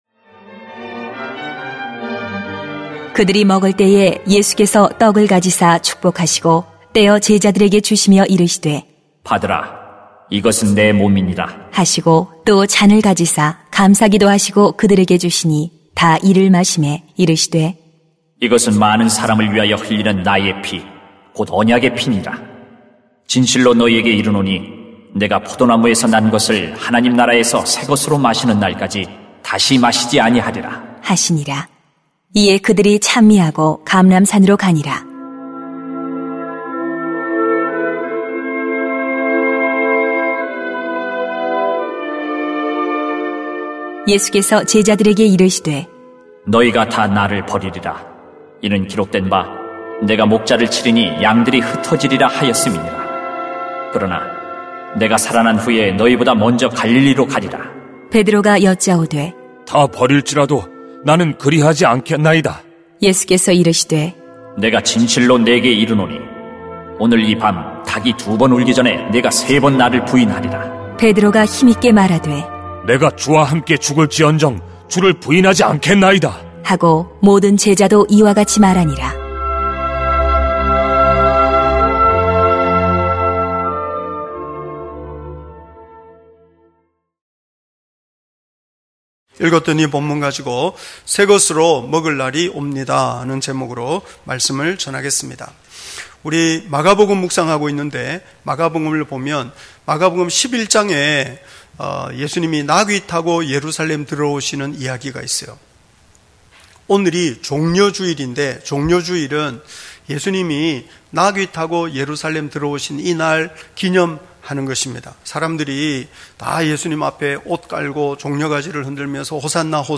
2020.4.5 새것으로 먹을 날이 옵니다 > 주일 예배 | 전주제자교회